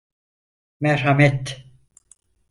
Pronunciado como (IPA)
/mɛɾ.ha.met/